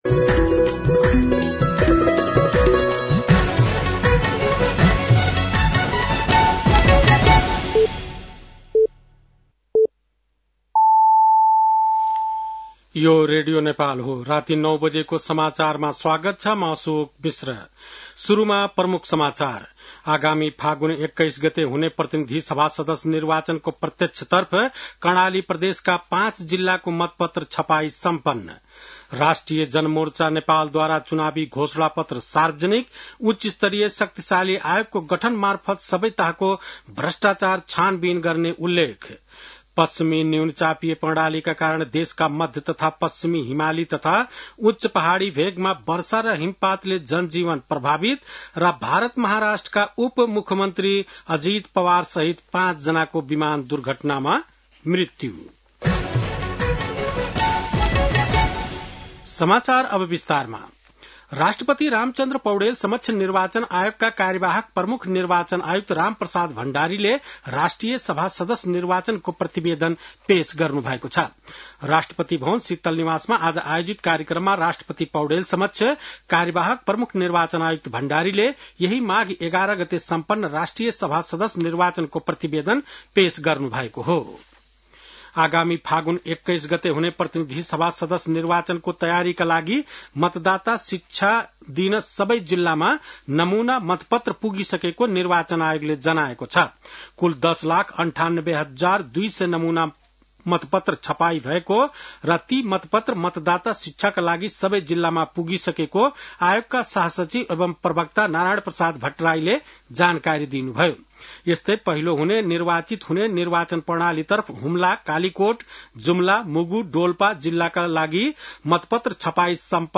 बेलुकी ९ बजेको नेपाली समाचार : १४ माघ , २०८२
9-PM-Nepali-NEWS-1-2.mp3